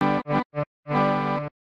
Sample sounds, mostly quite short